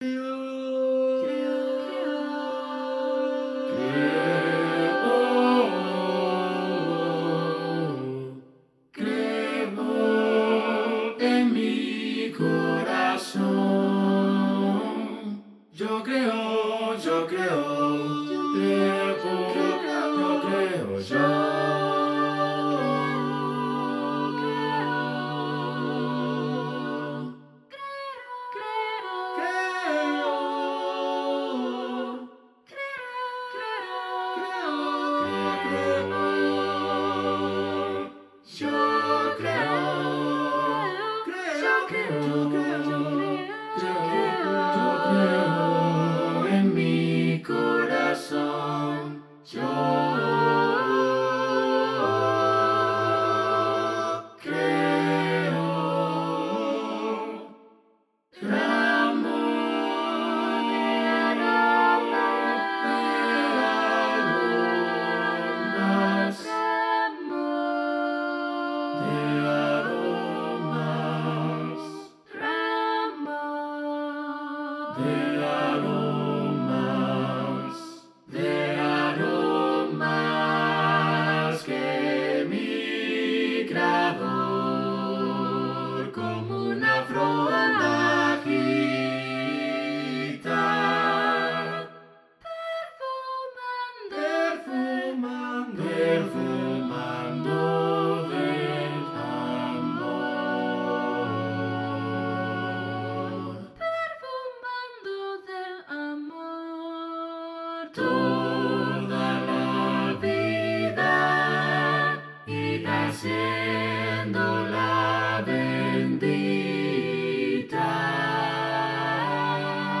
SATB a cappella 4’30”
SATB, divisi